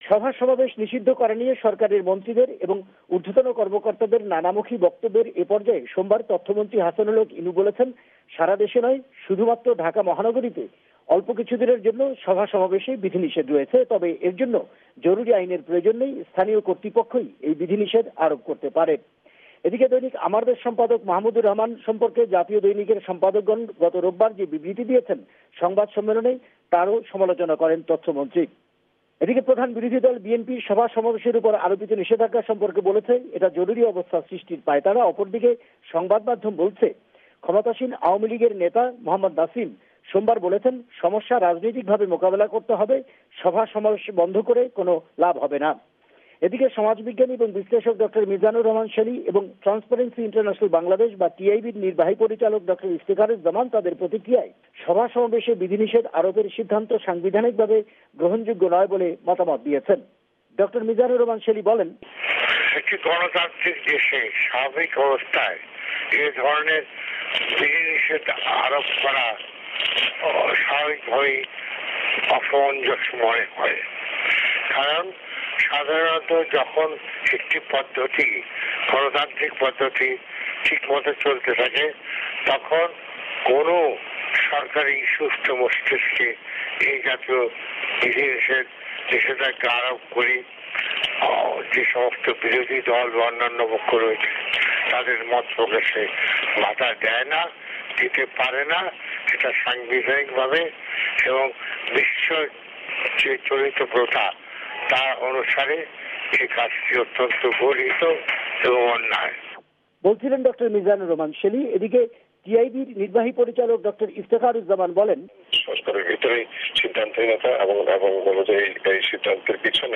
দু’ই বিশিষ্ট ব্যক্তির মন্তব্য সহ
রিপোর্ট